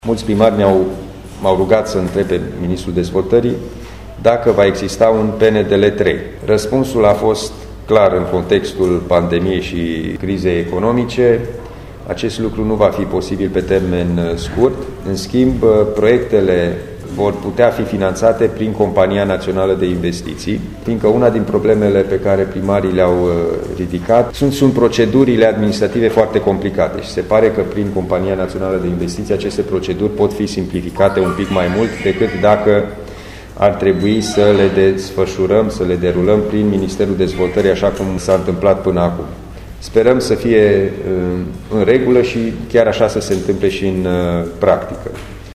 Alin Nica a explicat răspunsul primit de la ministru: